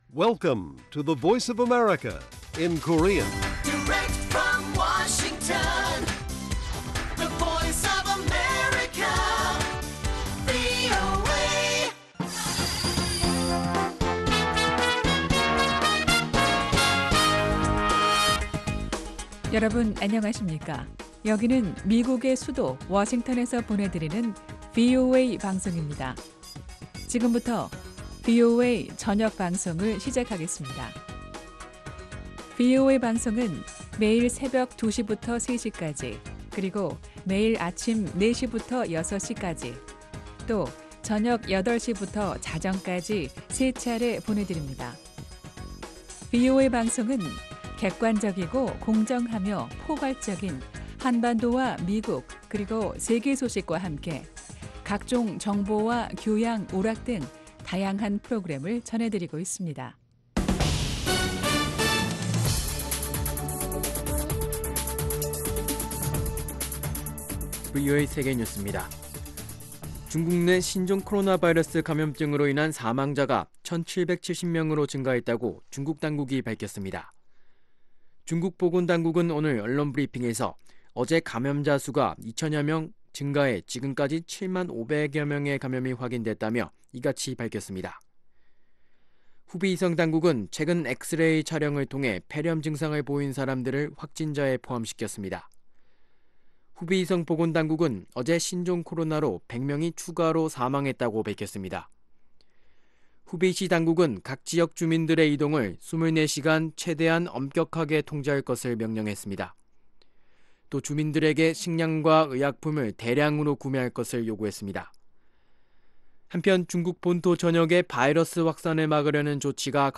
VOA 한국어 간판 뉴스 프로그램 '뉴스 투데이', 2019년 2월 17일 1부 방송입니다. 미국의 대북 제재는 북한의 핵 개발을 막기 위한 조치라고 마이크 폼페오 국무장관이 밝혔습니다. 마크 에스퍼 국방장관은 북한을 또다시 `불량국가'로 지칭했습니다. 미국 국방부는 2021 회계연도 안에 한반도 고고도 미사일 방어체계 사드의 개선 등 미사일 방어 전력의 통합을 완성하겠다고 밝혔습니다.